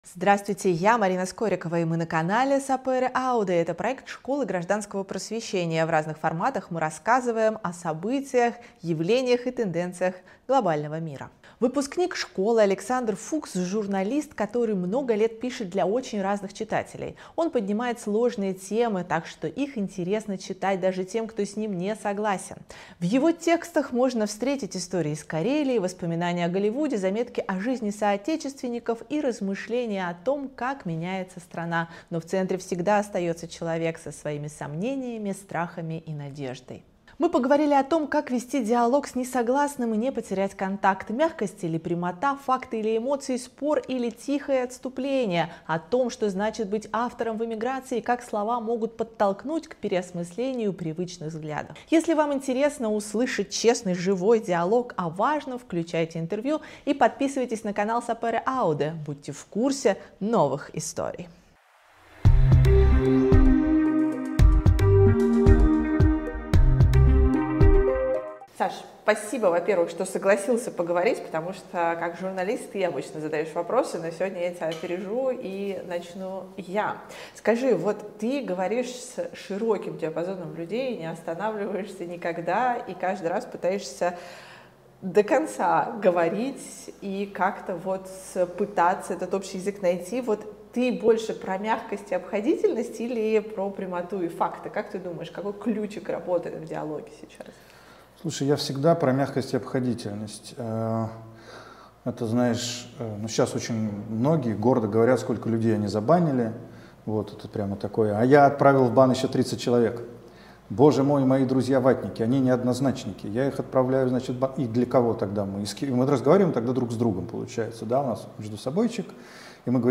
Разговор с журналистом